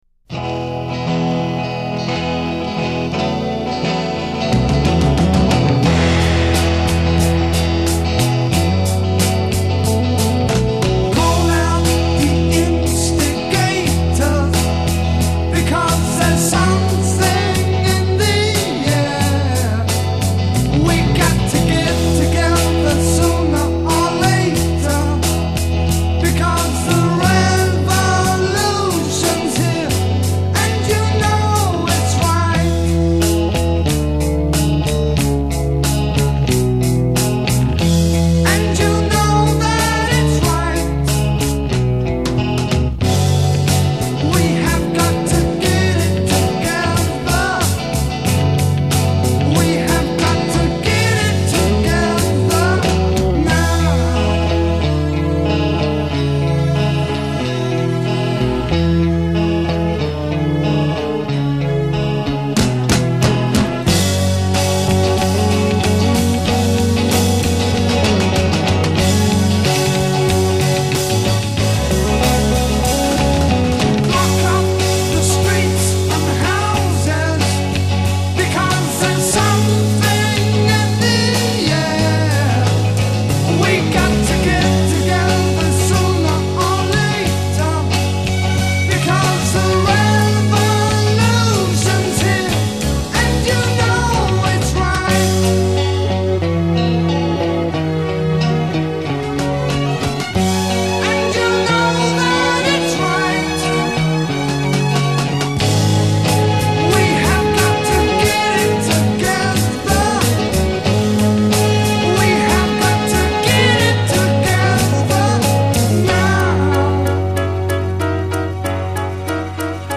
piano
guitar
bass guitar
Intro 4 Guitar, add second guitar, drums & bass
Intro. 4 Modulate up with full ensemble.
Transition 2 8 Build, piano with maracas, strings tremolo
Verse 8 + 9 Guitar doubles the voice. c